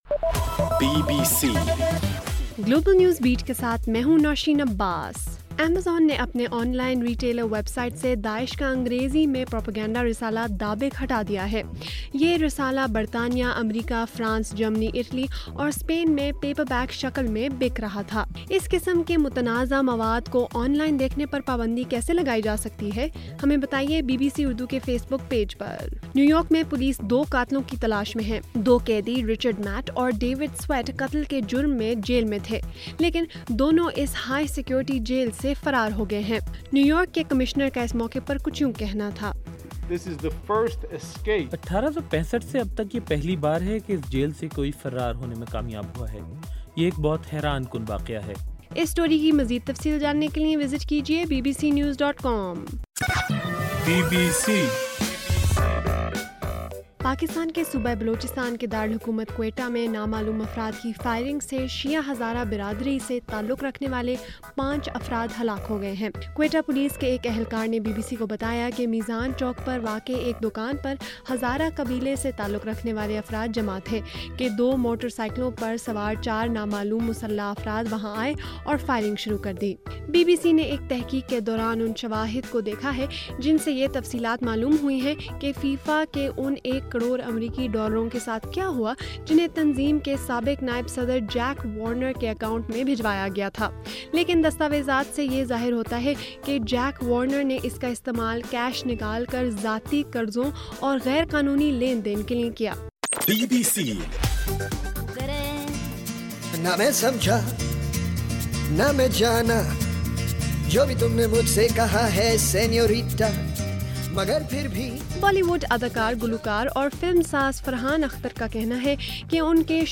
جون 7: رات 9 بجے کا گلوبل نیوز بیٹ بُلیٹن